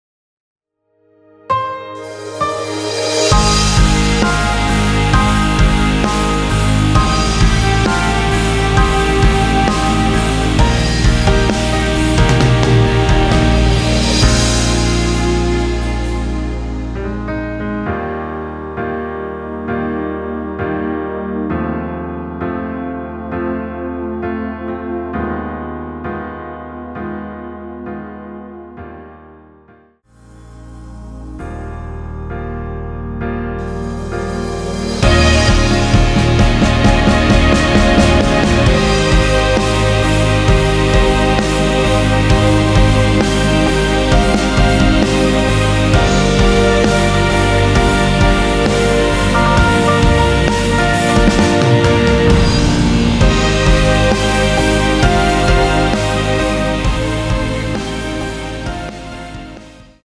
원키에서 (-1) 내린 MR 입니다.
Eb
앞부분30초, 뒷부분30초씩 편집해서 올려 드리고 있습니다.